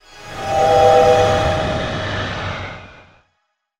light_in_dark_spell_03.wav